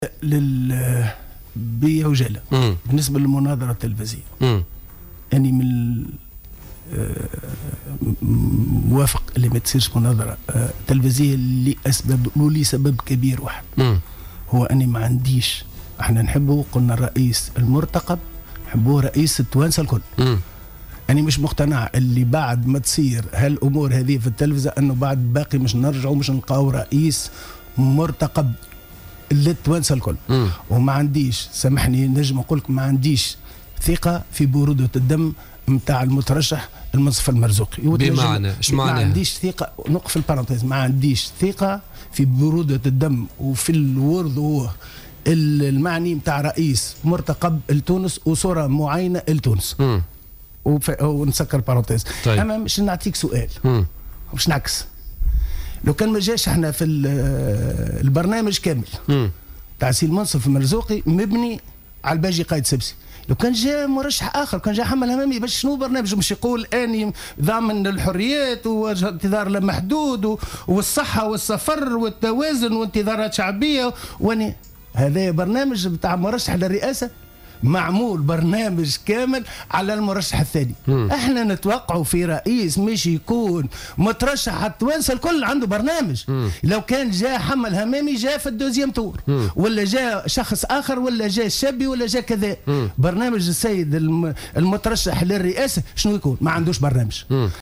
قال رضا شرف الدين، عضو مجلس نواب الشعب عن حركة نداء تونس ضيف برنامج "بوليتيكا" اليوم الخميس إنه يعارض فكرة إجراء مناظرة تلفزية بين المترشحيين للانتخابات الرئاسية المنصف المرزوقي والباجي قائد السبسي.